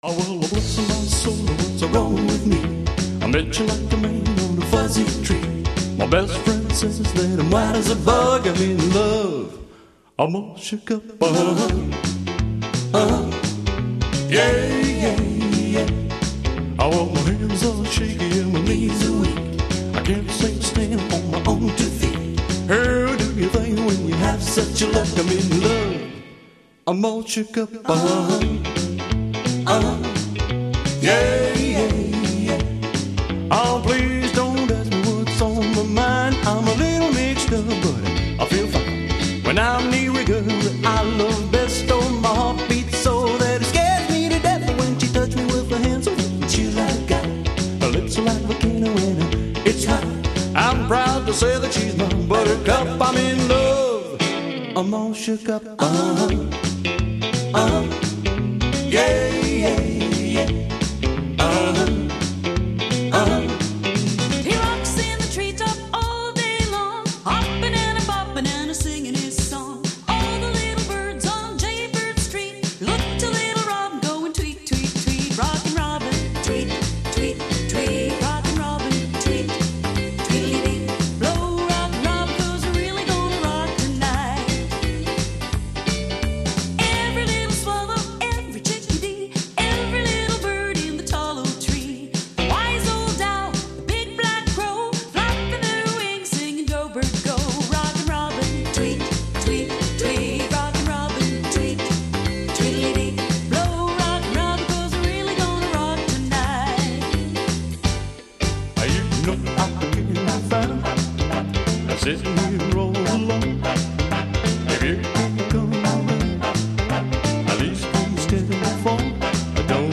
This Montreal Variety Duo is comprised of two Canadian-born singer-songwriters who have been close collaborators since 1988.
Montreal-Variety-Duo-Medley-Retro.mp3